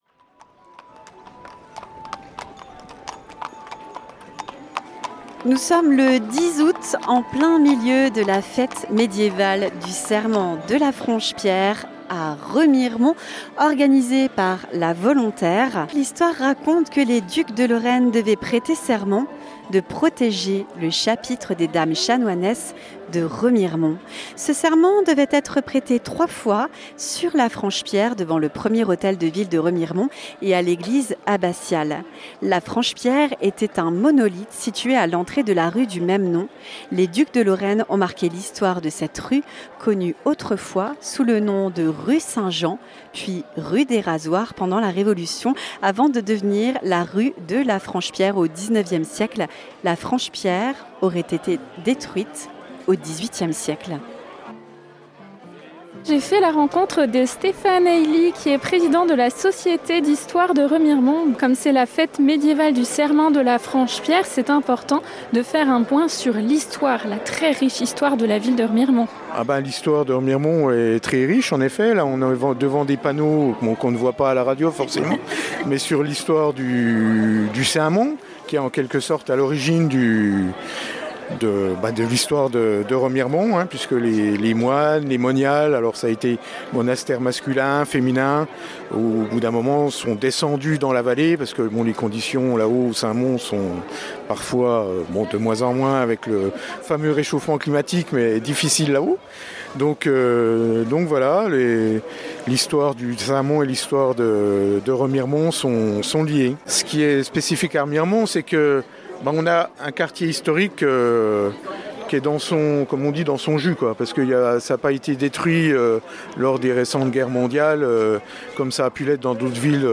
La fête médiévale du serment de la Franche Pierre de Remiremont : un voyage sonore au Moyen Âge